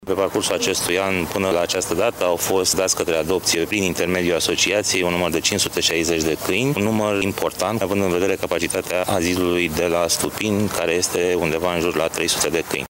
Numărul câinilor dați în adopție internațională a crescut la 560 de animale în acest an, spune viceprimarul Brașovului, Ciprian Bucur.